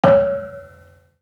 Gamelan Sound Bank
Kenong-dampend-C#4-f.wav